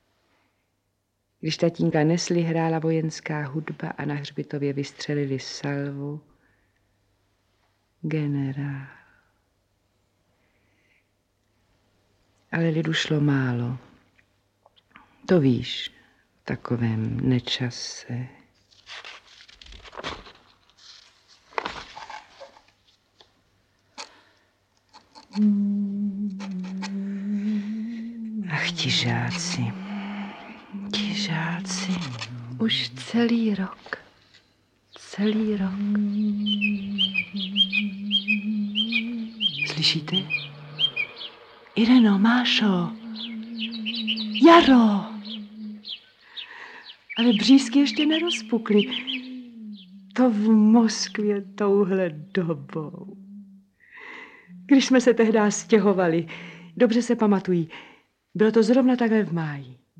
Audiobook
Read: Otomar Korbelář